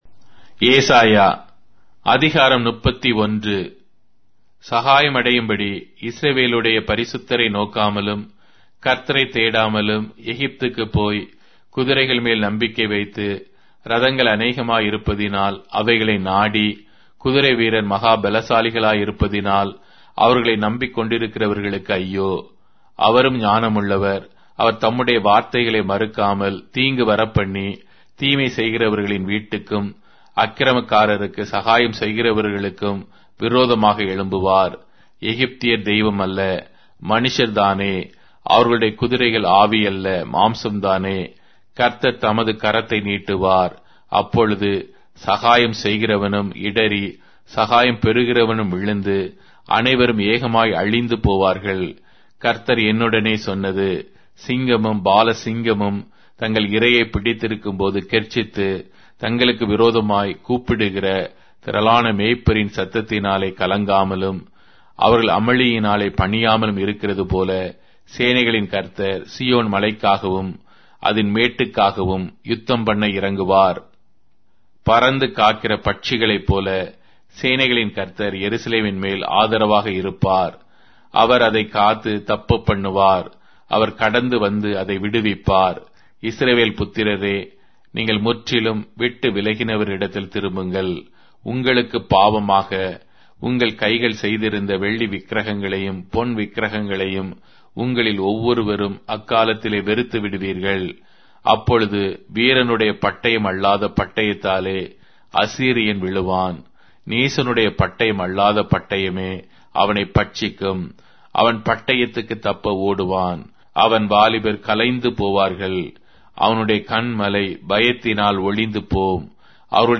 Tamil Audio Bible - Isaiah 51 in Mhb bible version